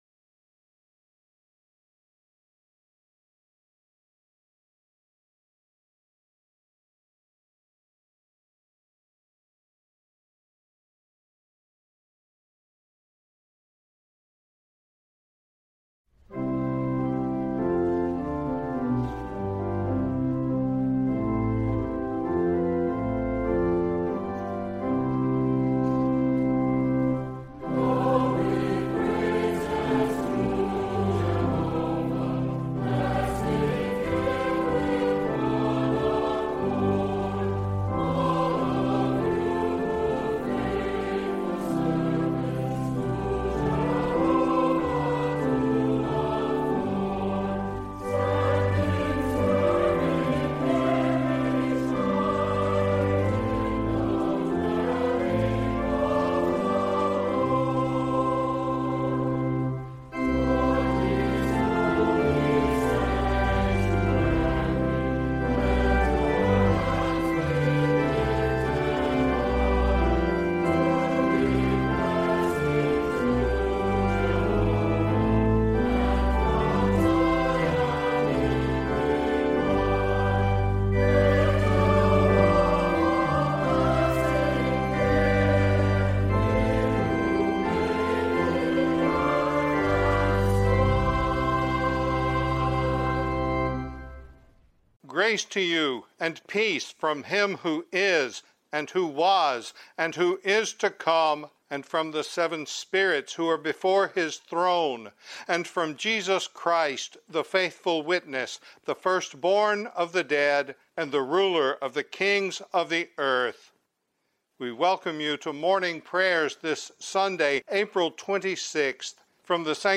Morning Prayer, 3rd Sunday in Eastertide | Ozark Presbyterian Church (PCA)
Ozark Presbyterian Church (PCA) Daily Prayer from the Chapel at Ozark Presbyterian Church